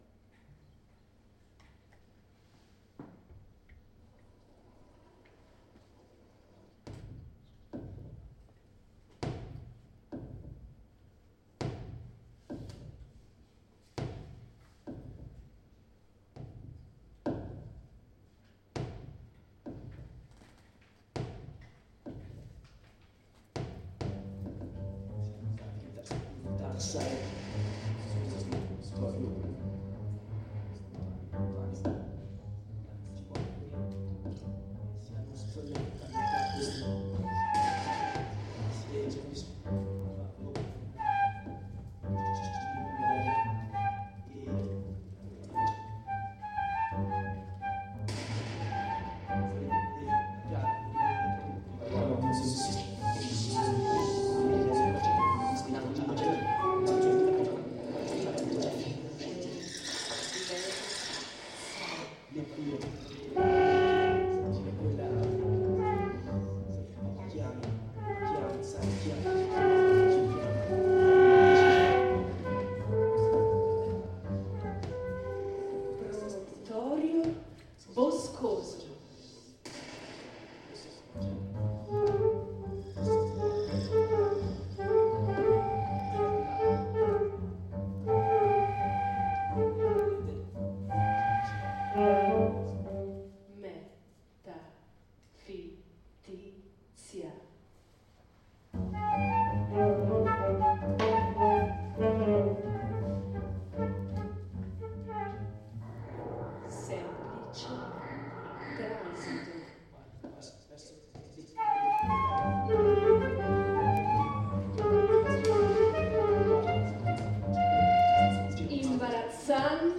File audio del Concerto, di compositori e interpreti del Conservatorio Bonporti, nell’ambito del Festival di poesia Anterem a Verona
Il concerto si è tenuto domenica 24 ottobre alla Biblioteca Civica di Verona, alle ore 11.00.
Dianna Dmitrijeva (1969), Viaggio attraverso la gioventù per voce recitante, flauto, sassofono contralto, contrabbasso, elettronica – testo di Lorenzo Montano